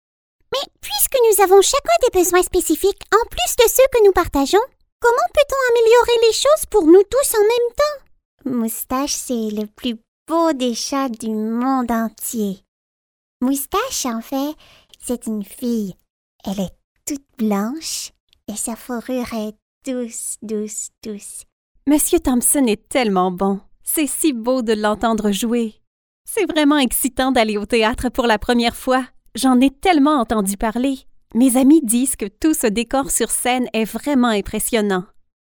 French Canadian Character voice Demo
French Canadian, Quebec French, neutral French
Young Adult
Middle Aged